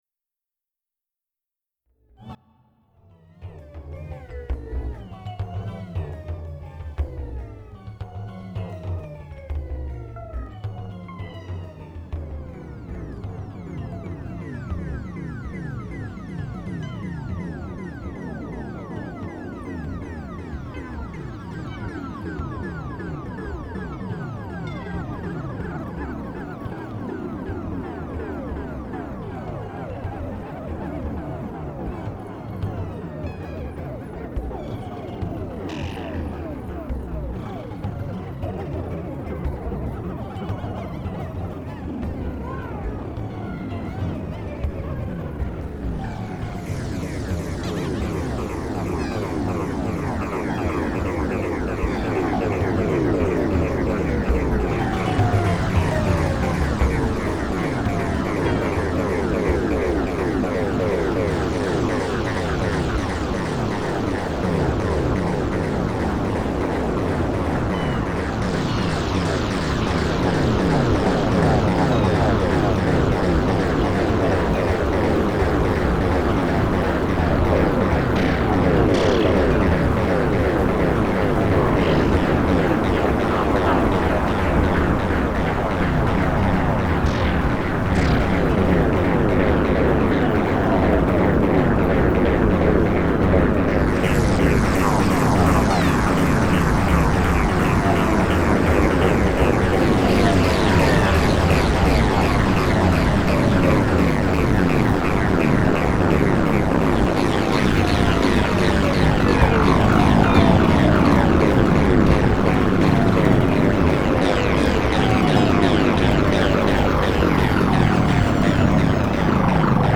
Pulsing Correlated Activations around regular_Pulsarissimus emissarium: regular pulsameness of rRadio waves-wives or/ut other electromagnetic radiation, at rates, of up, to one million, billion, countable – count on us – pulses-points per inner-second.